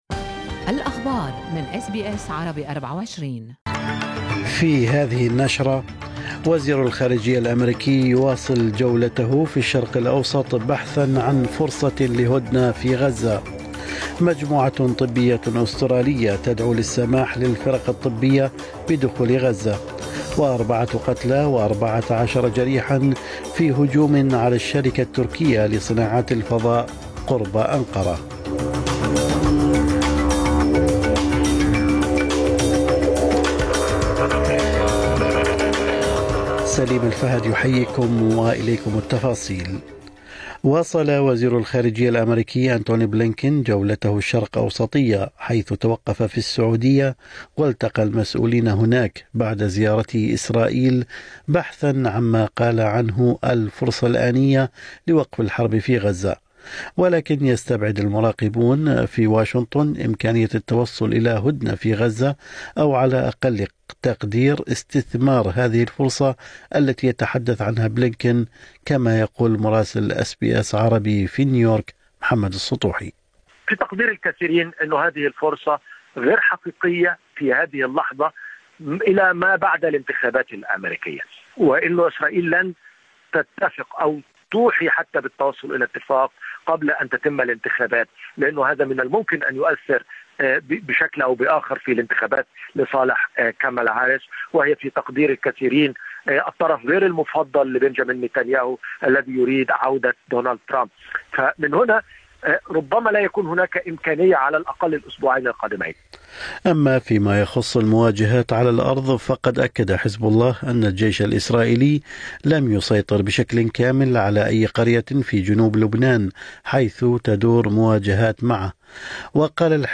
نشرة أخبار الصباح 24/10/2024